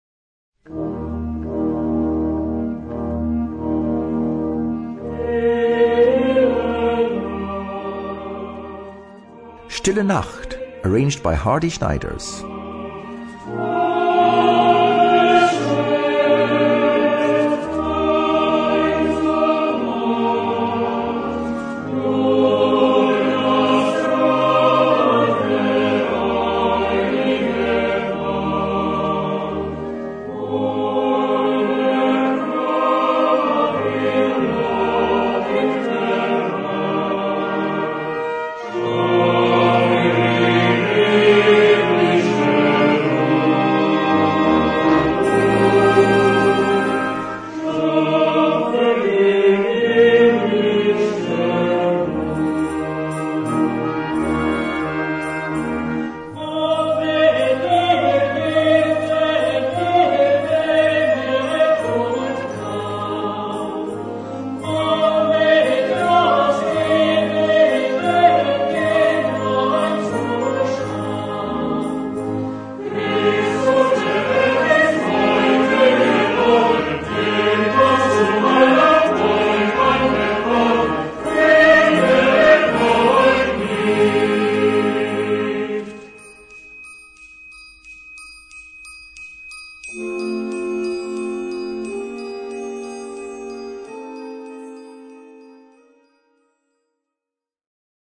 Without choir!
Partitions pour orchestre d'harmonie et fanfare.